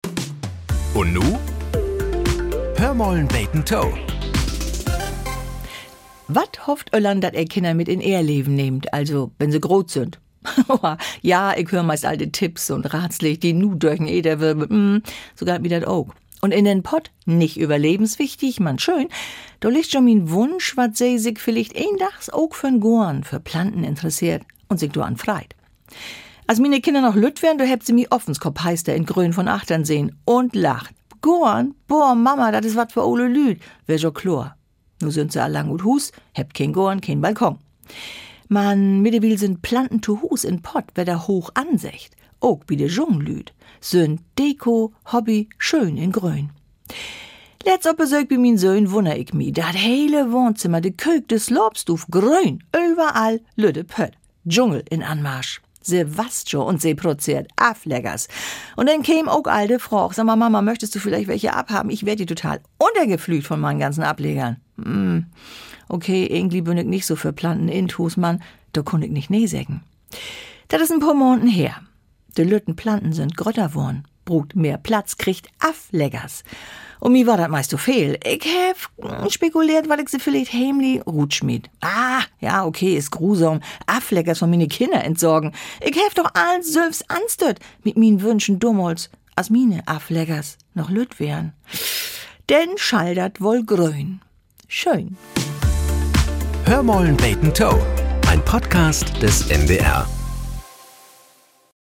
Nachrichten - 24.01.2025